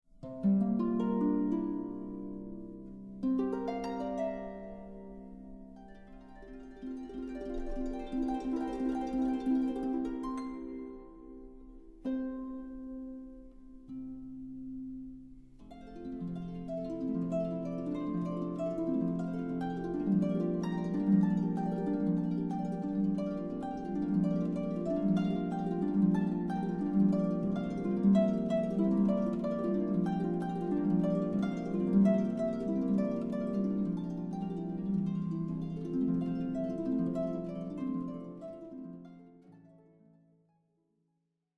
A beautiful cd with 77 minutes harp solo music.